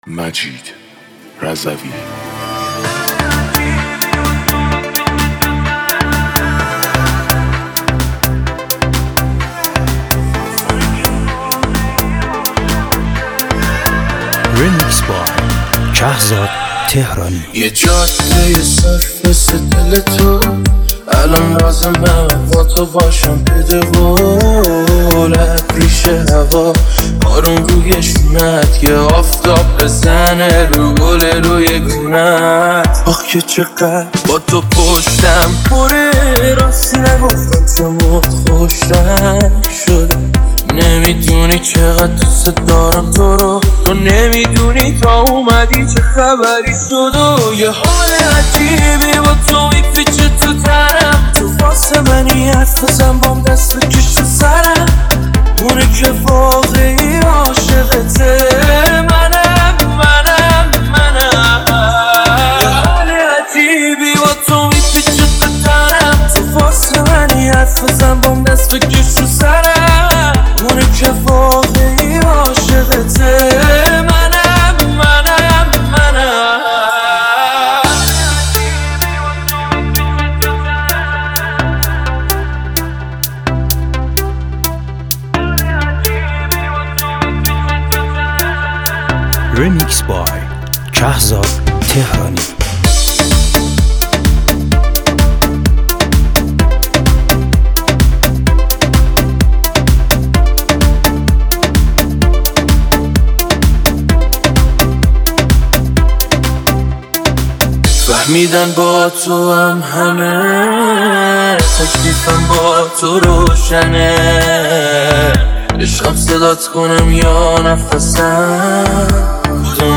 ریمیکس شاد تند بیس دار سیستمی
ریمیکس بیس دار تند
شاد بیس دار سیستمی